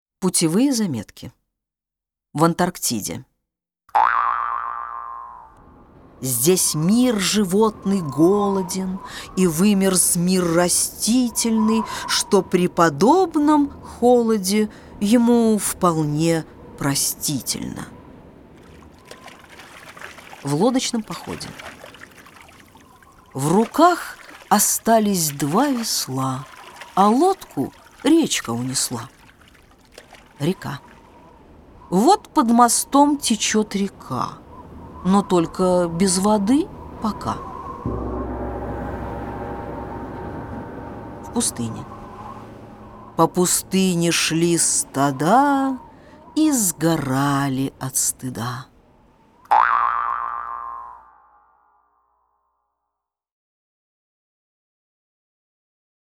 Аудиокниги
Исполнитель аудиокниги: Дина Рубина